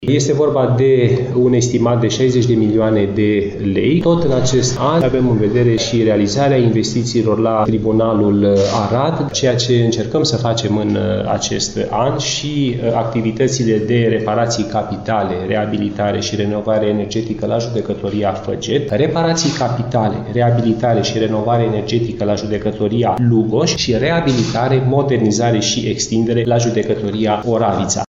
Prezent la Timișoara, ministrul Justiției, Radu Marinescu, a declarat că obiectivele propuse de minister pentru anul 2025 vizează proiectele de investiții.